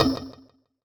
Percussion 01.wav